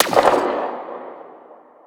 Zapper_far_01.wav